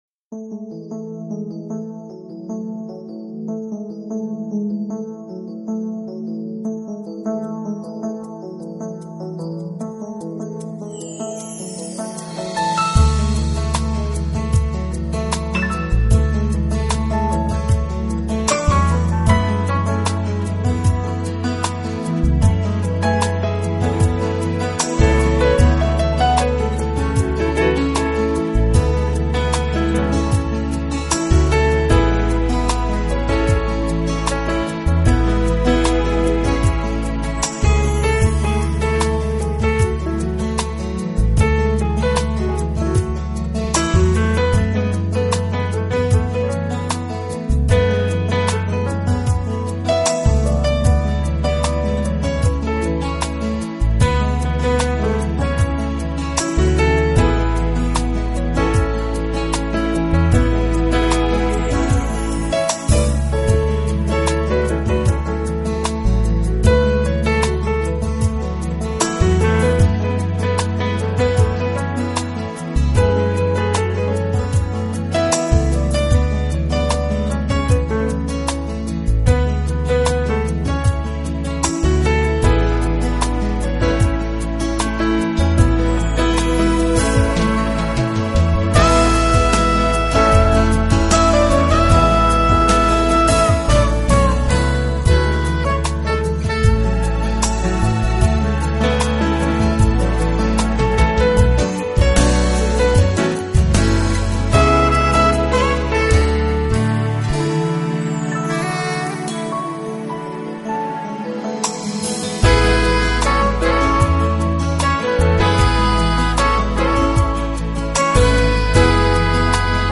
音乐流派：Jazz